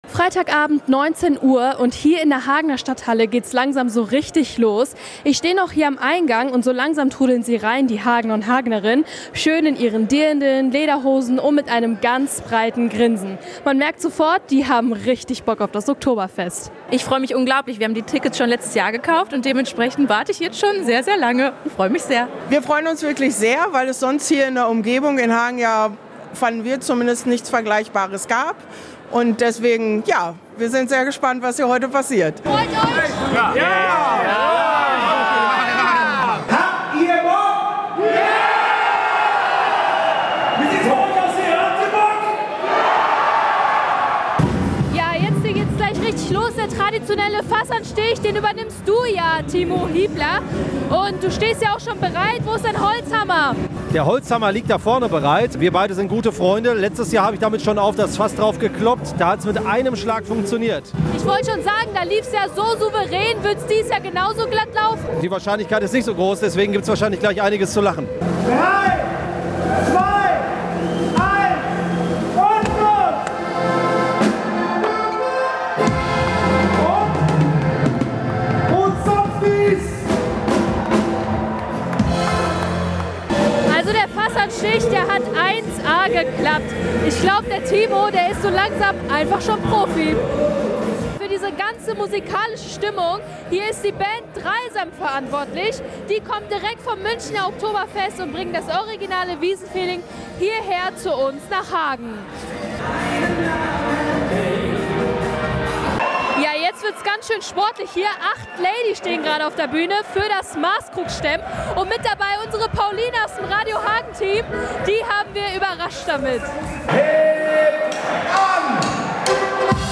Was für ein Abend in der Hagener Stadthalle!
Rund um die Bühne, an den langen Tischen und auf den Bänken herrscht ausgelassene Stimmung – die erste Maß ist längst geleert, und die Gäste feiern als gäbe es kein Morgen.
reportage-oktoberfest-2025.mp3